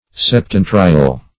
Septentrial \Sep*ten"tri*al\, a.
septentrial.mp3